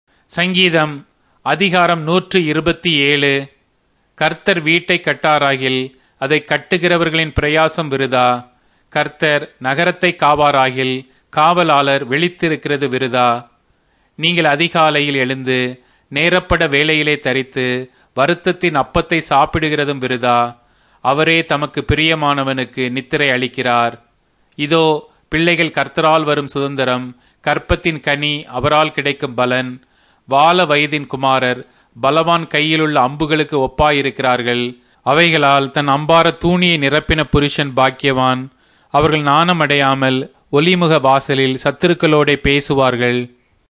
Tamil Audio Bible - Psalms 4 in Tov bible version